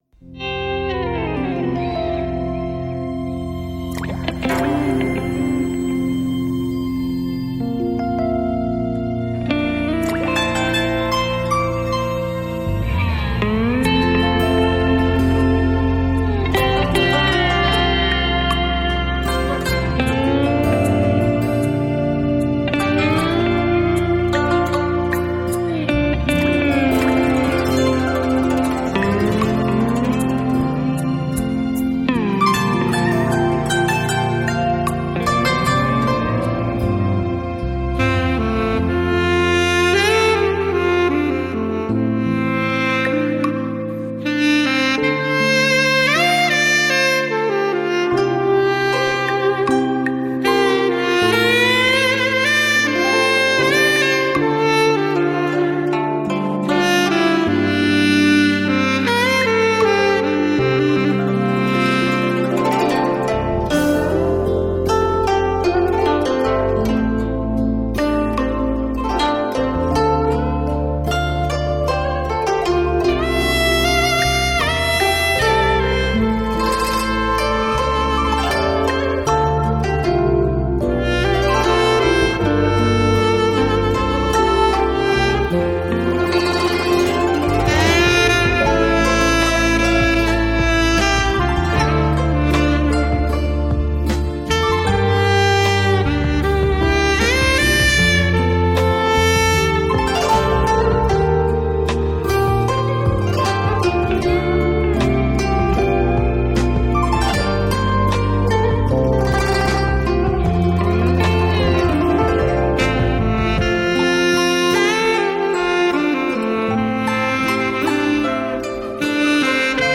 Surround 7.1德国技术STS三维高临场音效，美国最新技术高清录音紫水晶CD。